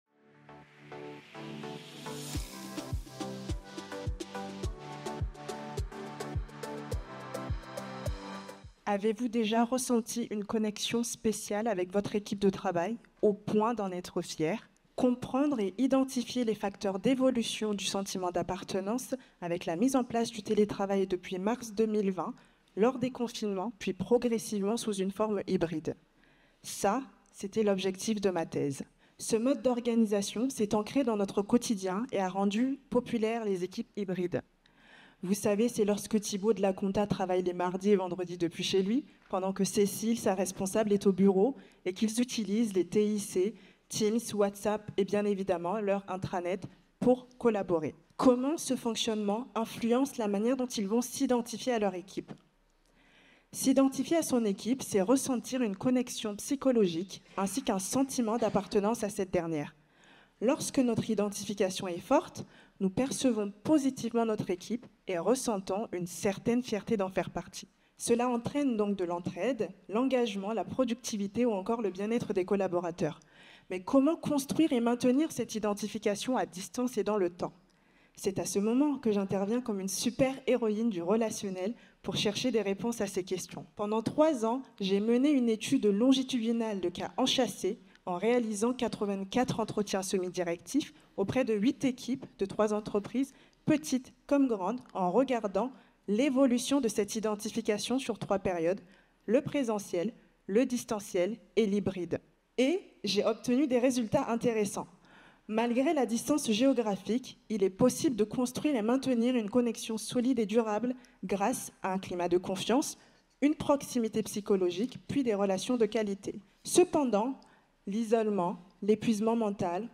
Pitch pour le Prix FNEGE de la Meilleure Thèse en Management 2024 (thèse en 180 secondes) – Prix de thèse AGRH Depuis la crise de la Covid-19, le télétravail et le travail hybride se sont installés dans les organisations et ont engendré des transformations accrues.